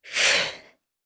Hilda-Vox_Casting1_jp_b.wav